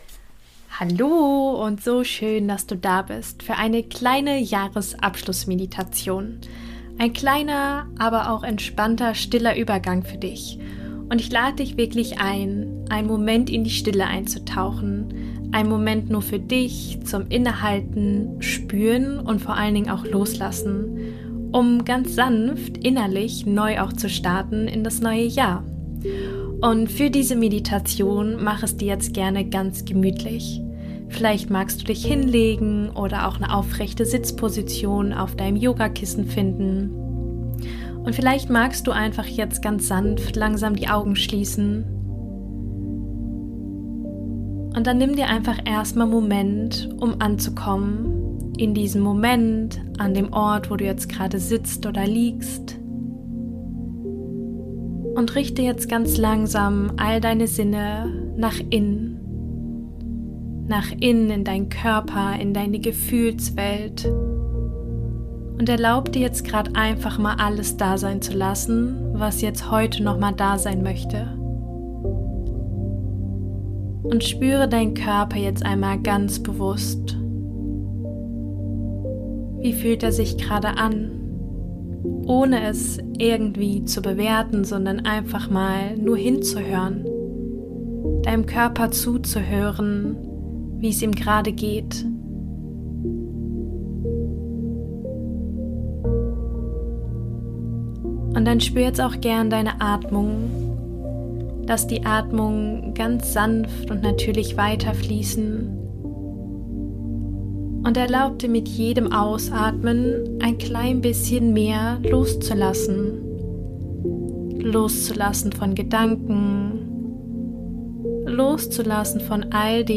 Jahresabschluss-Meditation – ein stiller Übergang für dich